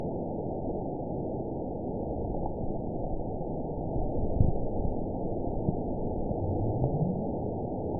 event 920446 date 03/26/24 time 01:00:01 GMT (1 year, 2 months ago) score 9.67 location TSS-AB05 detected by nrw target species NRW annotations +NRW Spectrogram: Frequency (kHz) vs. Time (s) audio not available .wav